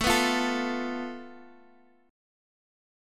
Asus2#5 chord